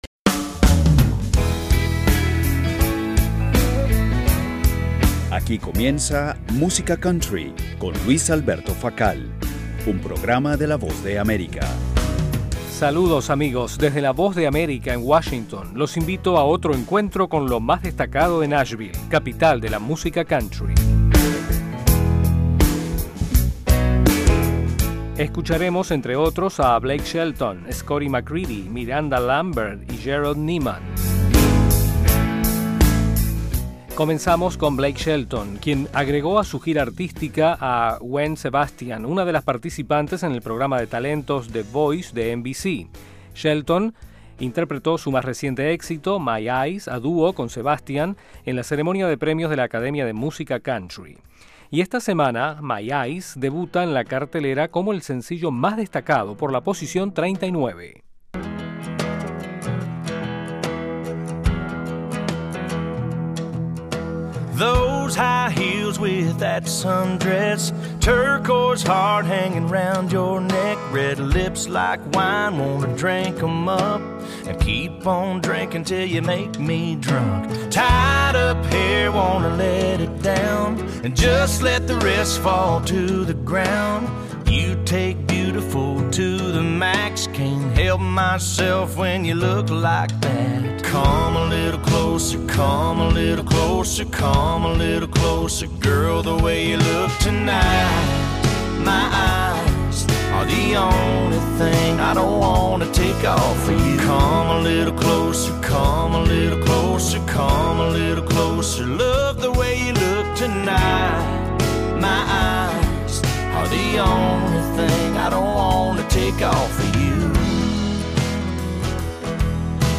programa musical